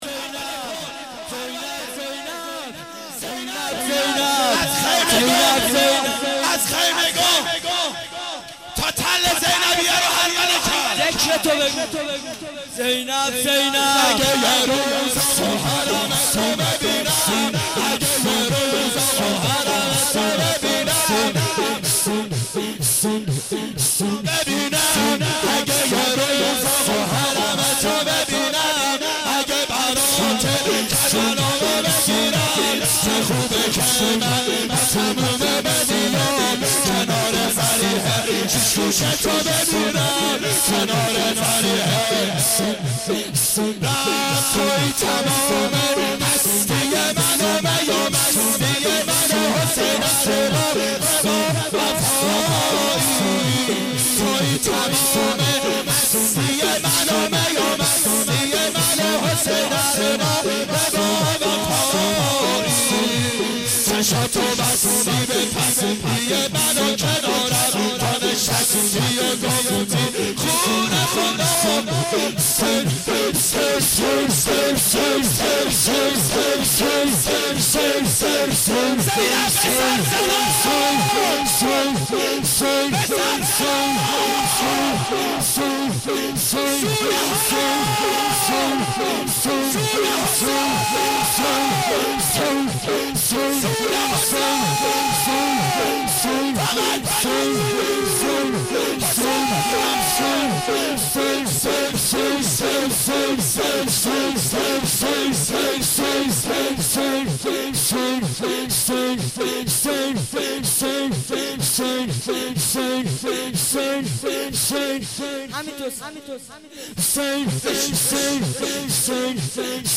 گلچین جلسات هفتگی
شور.mp3